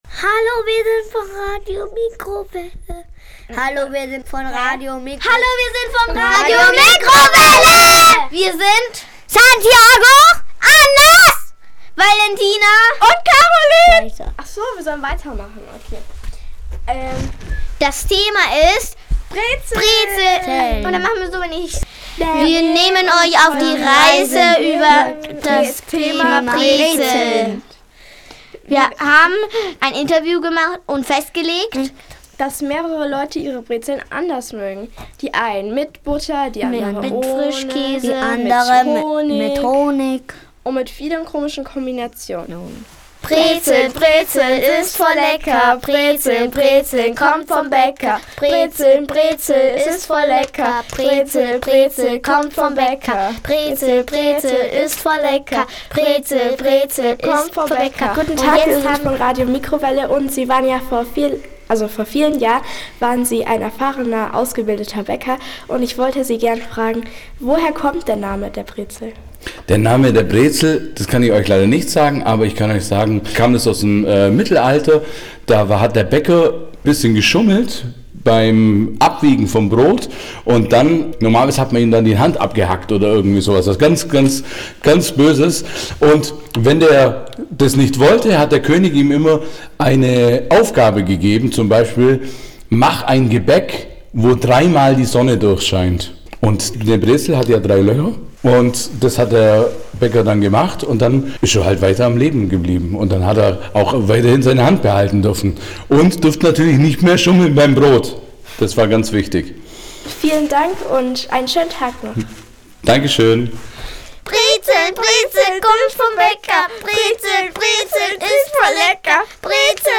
Mit Mikrofonen bewaffnet ziehen wir gemeinsam durch die Stadt und fragen die Ulmer*innen nach ihrer Meinung.